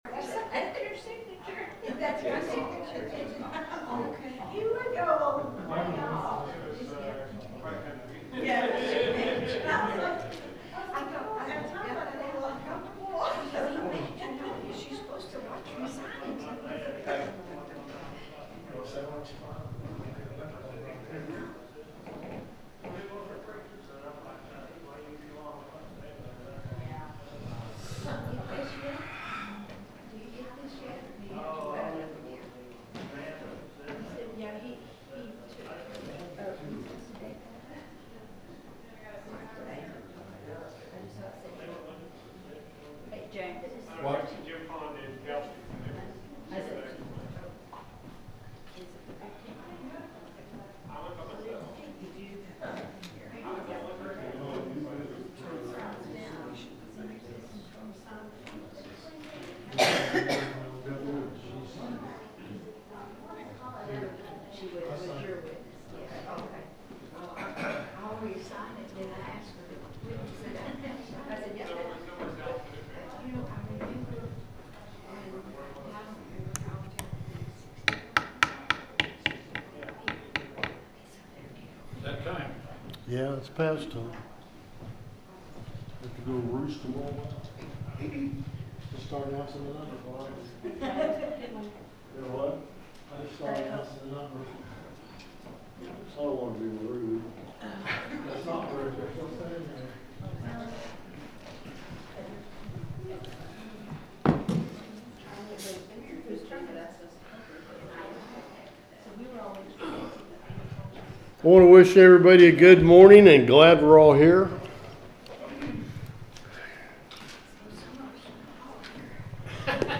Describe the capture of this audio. The sermon is from our live stream on 3/15/2026